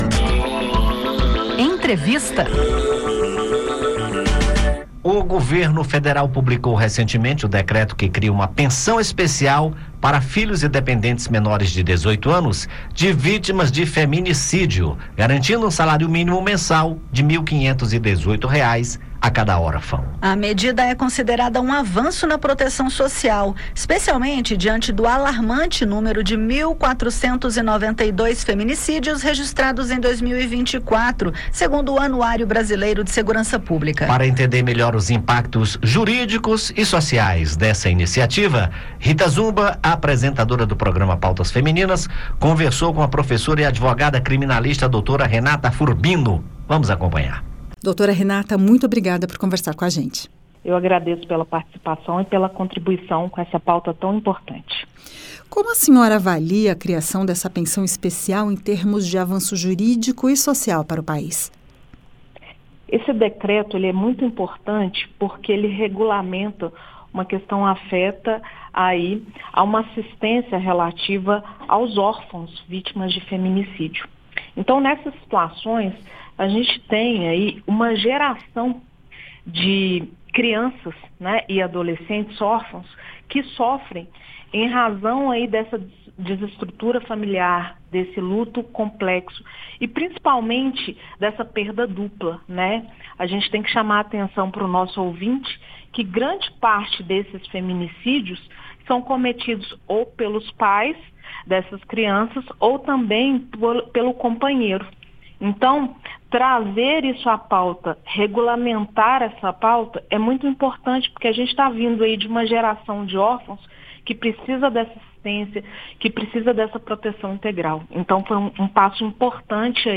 Advogada comenta pensão especial para filhos de vítimas de feminicídio